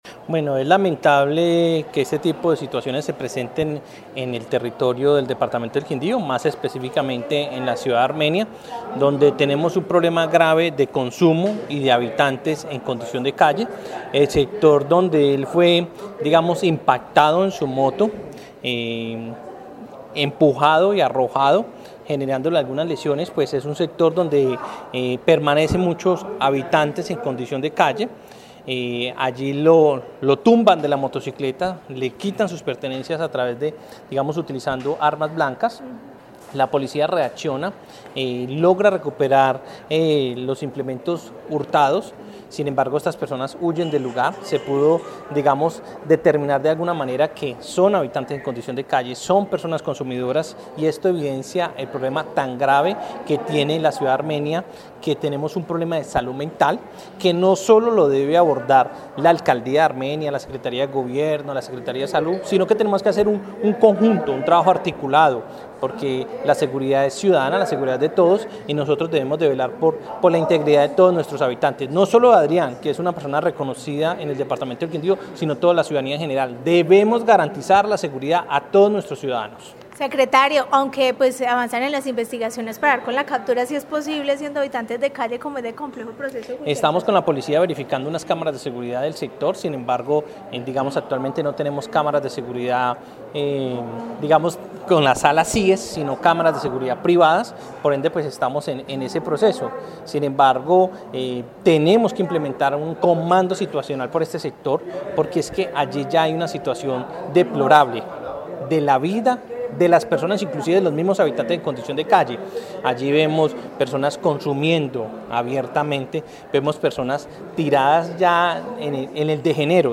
Secretario del Interior del departamento, Jaime Andrés Pérez